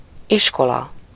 Ääntäminen
Ääntäminen France (Paris): IPA: [ɛ̃ kɔ.lɛʒ] Tuntematon aksentti: IPA: /kɔ.lɛʒ/ Haettu sana löytyi näillä lähdekielillä: ranska Käännös Ääninäyte 1. iskola Suku: m .